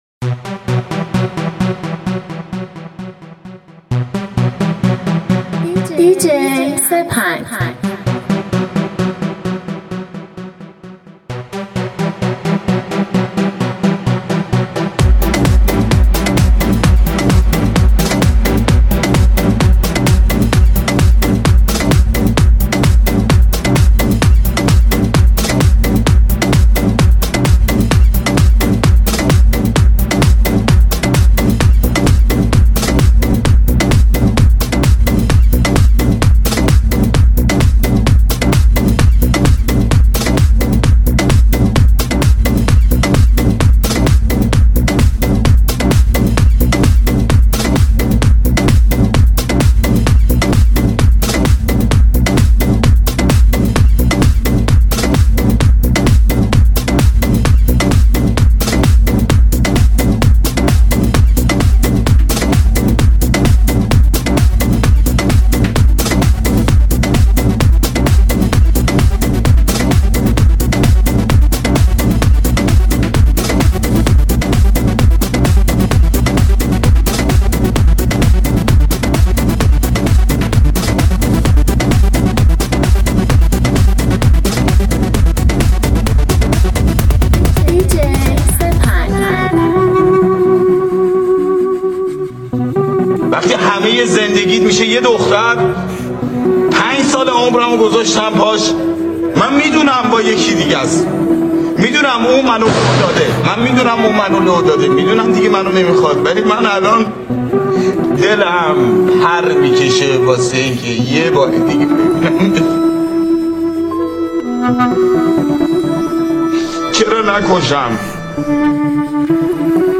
ریمیکس
موسیقی بی کلام